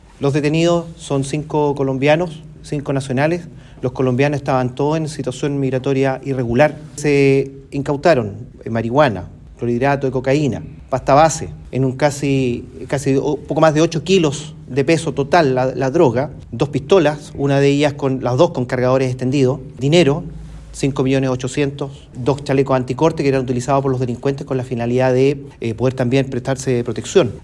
En uno de los domicilios se desbarató incluso un laboratorio que era utilizado para elaborar y dosificar la droga, según lo dio a conocer el prefecto de Carabineros en Bío Bío, coronel Marcelo Salas Carvacho.